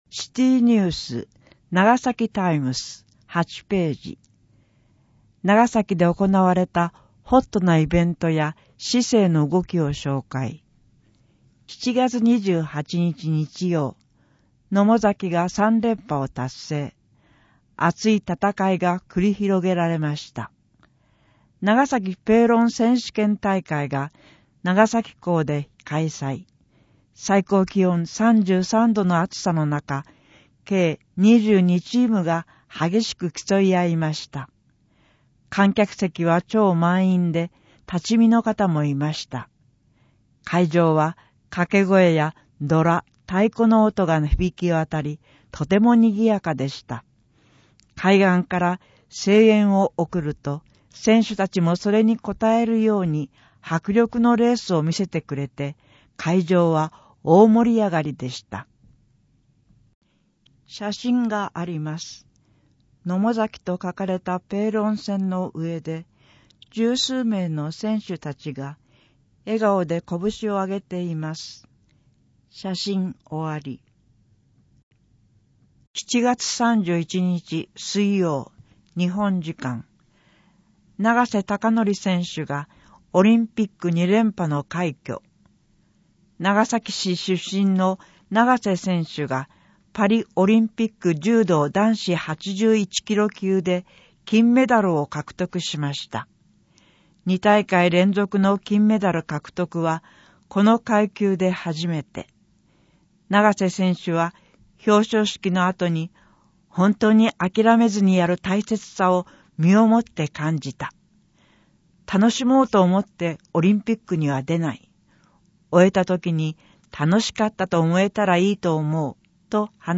声の広報ながさき 令和6年9月号 No.884